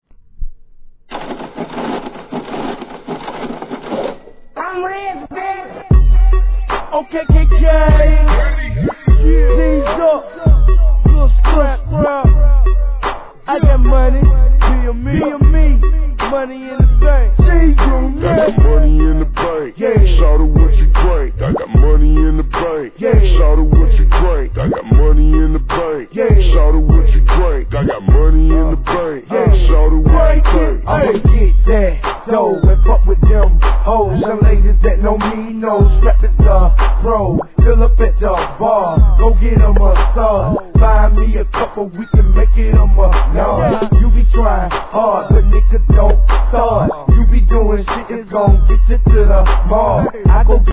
G-RAP/WEST COAST/SOUTH
ゆったりとした悪そうなトラックに